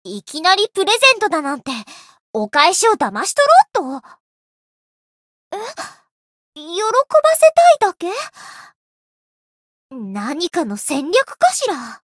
灵魂潮汐-叶月雪-情人节（送礼语音）.ogg